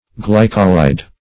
Search Result for " glycolide" : The Collaborative International Dictionary of English v.0.48: Glycolide \Gly"co*lide\, n. [Glycol + anhydride.]